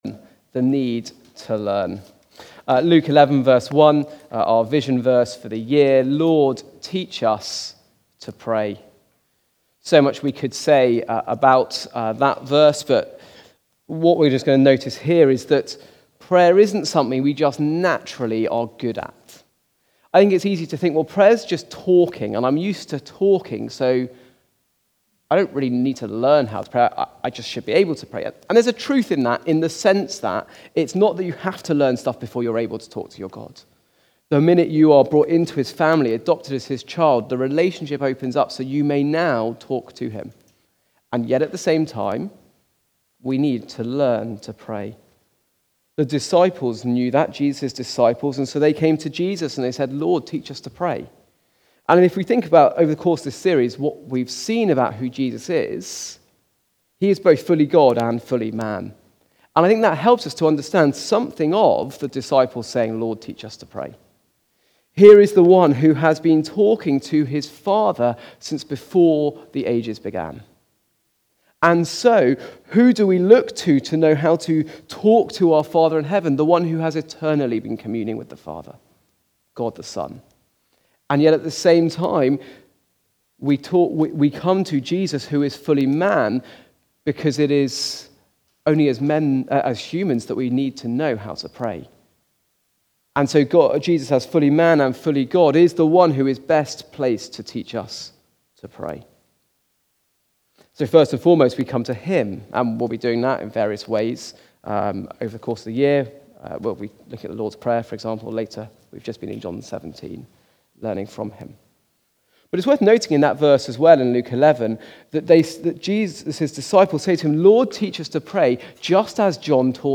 Learning to Pray from the series Learning From Our Forefathers. Recorded at Woodstock Road Baptist Church on 06 April 2025.